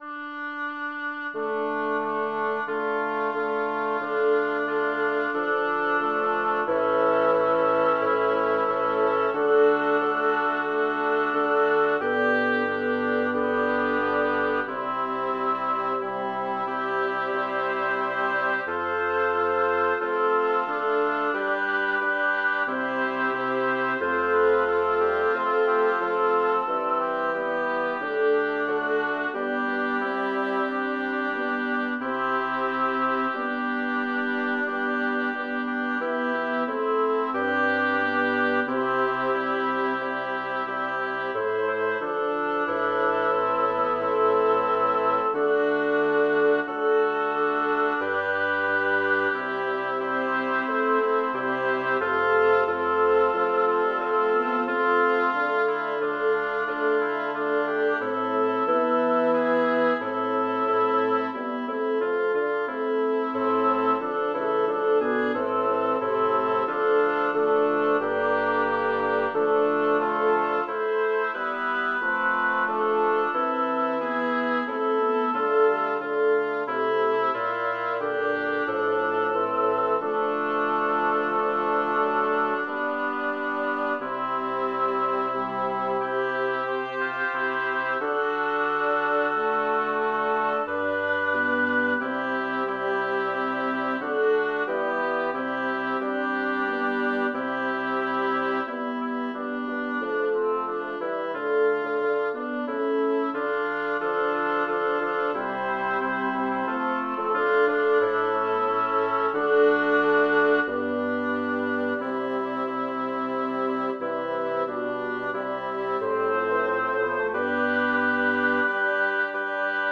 Title: Cinto di ardenti voglie Composer: Vincenzo Bellavere Lyricist: Number of voices: 6vv Voicing: SAATTB Genre: Secular, Madrigal
Language: Italian Instruments: A cappella